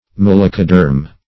Search Result for " malacoderm" : The Collaborative International Dictionary of English v.0.48: Malacoderm \Mal"a*co*derm\, n. [Gr. malako`s soft + ? skin.]